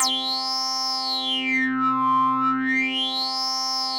C4_wasp_lead_1.wav